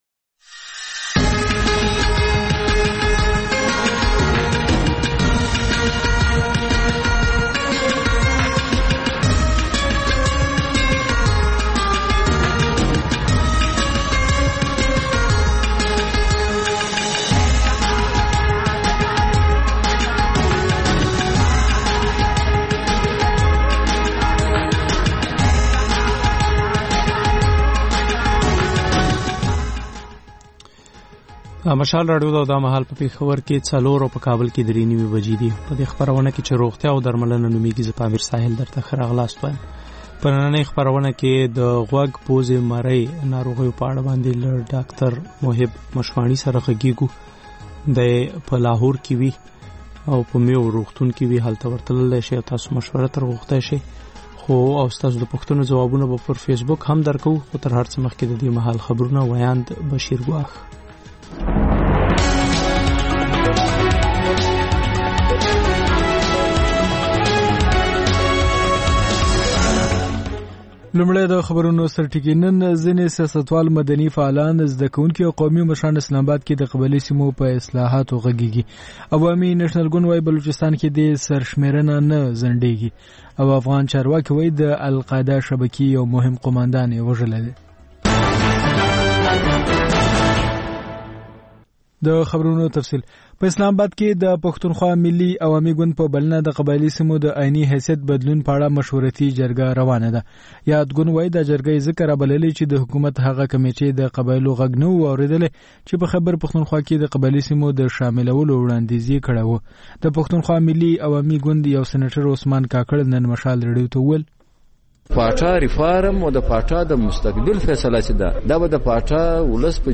د مشال راډیو مازیګرنۍ خپرونه. د خپرونې پیل له خبرونو کېږي. د دوشنبې یا د ګل پر ورځ د روغتیا په اړه ژوندۍ خپرونه روغتیا او درملنه خپرېږي چې په کې یو ډاکتر د یوې ځانګړې ناروغۍ په اړه د خلکو پوښتنو ته د ټیلي فون له لارې ځواب وايي.